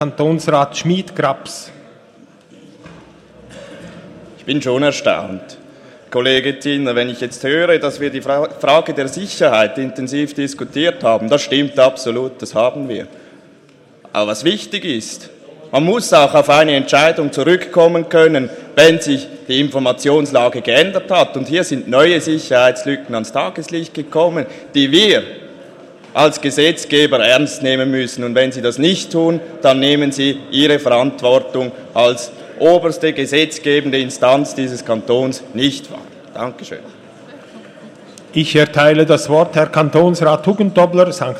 Session des Kantonsrates vom 26. bis 28. November 2018